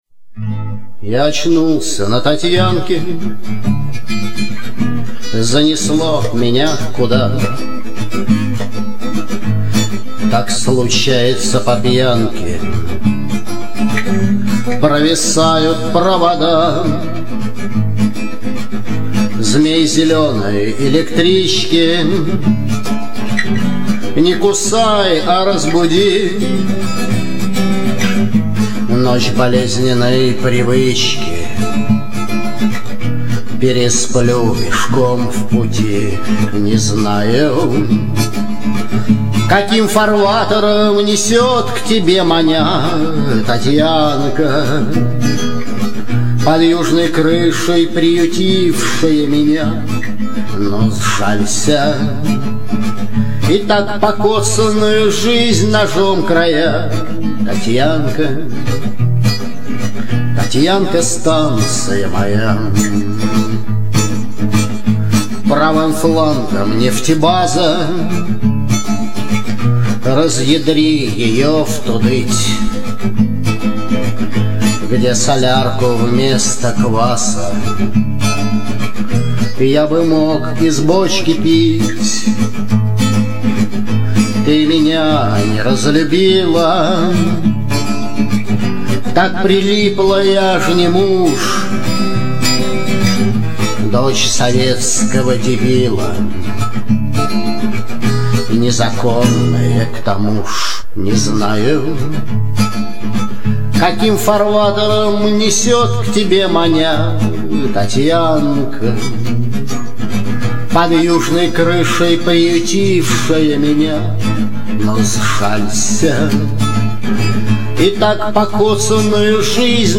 Романтическая музыка Лирическая музыка Авторские песни